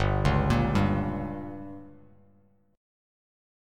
Gdim chord